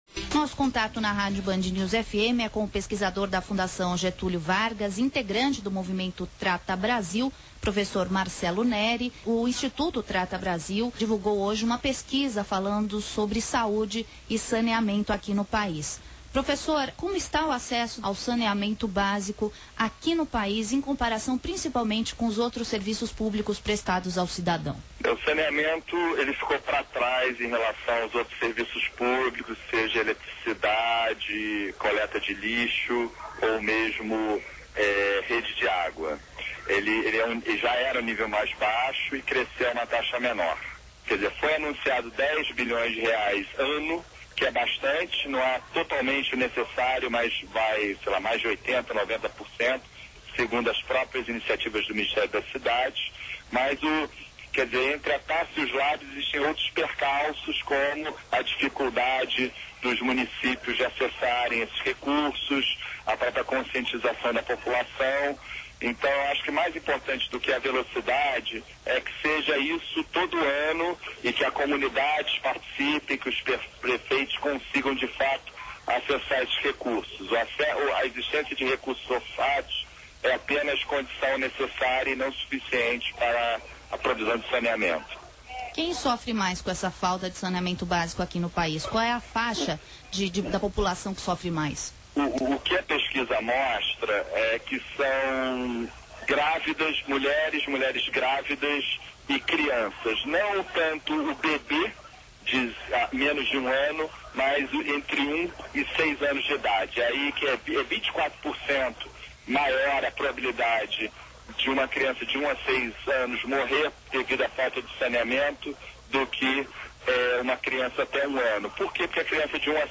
Novembro/2007 Entrevista com Marcelo Neri pesquisador da FGV Meio: Rádio Band News - RJ Mídia: Rádio Temas / Subtemas Desenv.